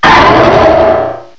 sovereignx/sound/direct_sound_samples/cries/lucario_mega.aif at master